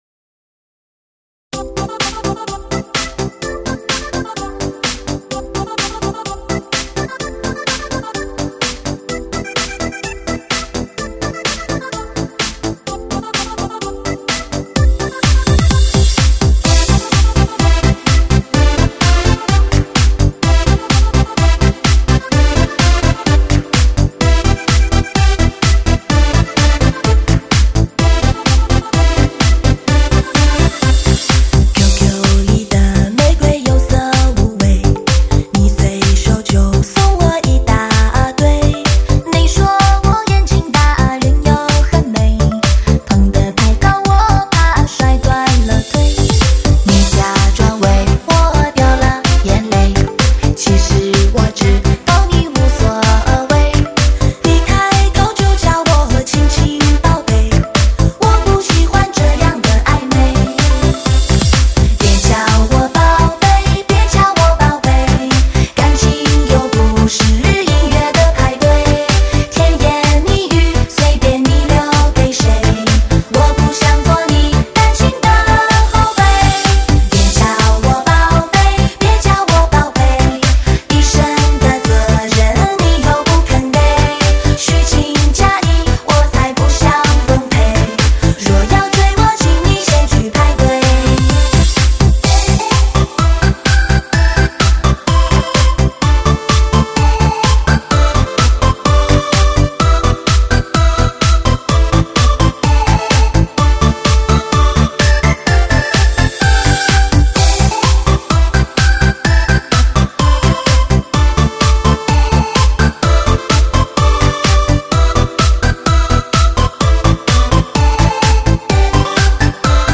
唱片类型：电音舞曲